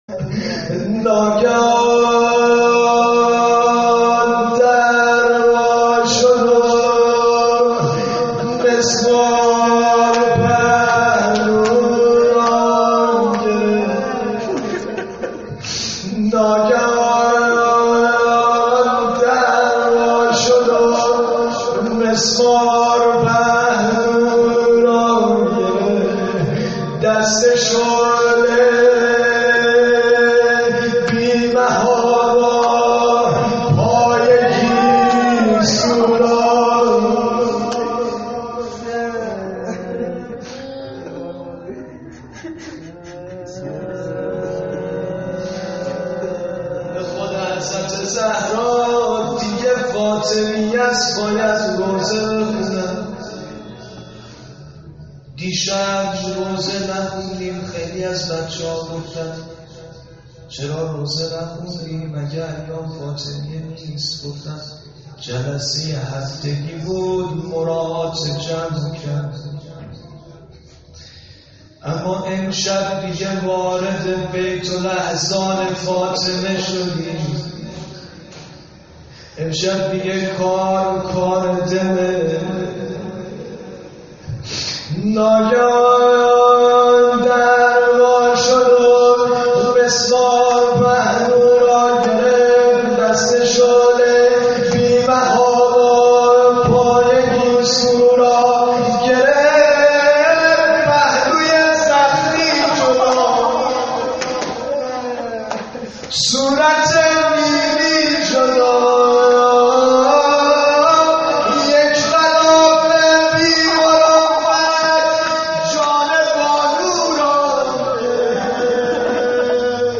خیمه گاه - محبان باب الحوائج - روضه شب اول فاطمیه 97